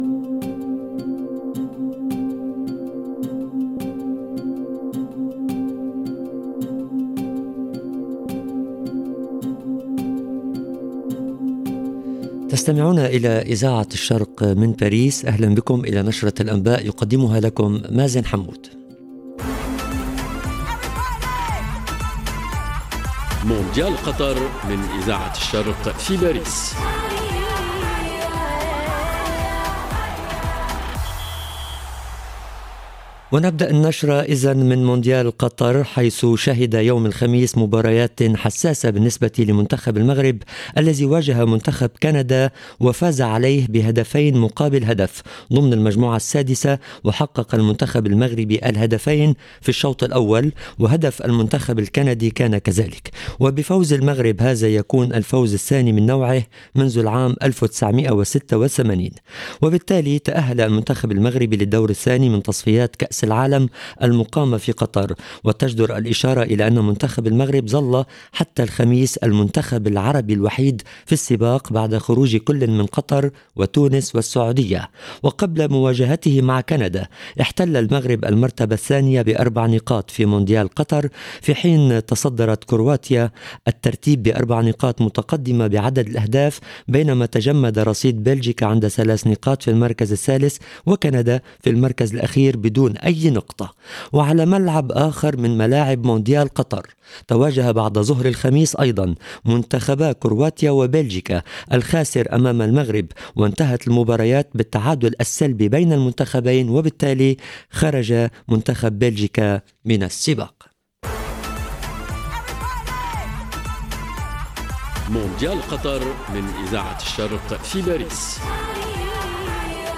EDITION DU JOUNAL DE 18H EN LANGUE ARABE